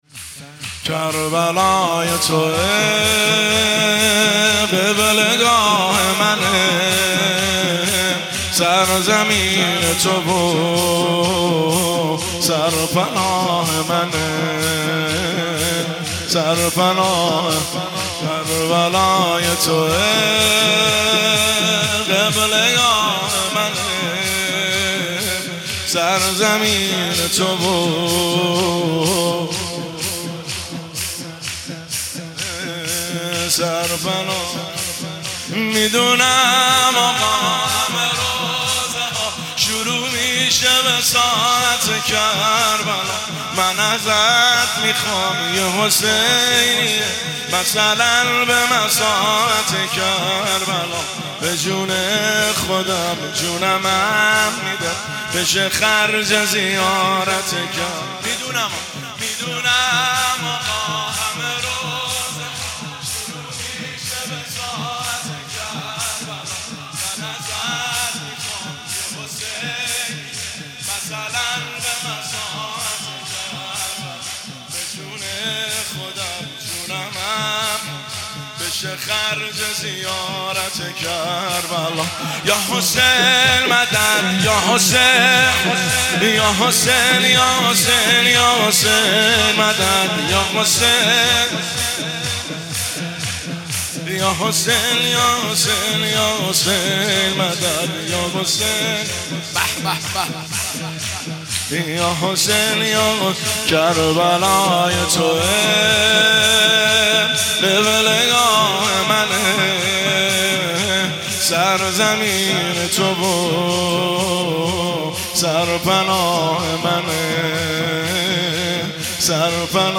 مداحی شور قدیمی احساسی زیبا
شهادت حضرت ام البنین (س) 1403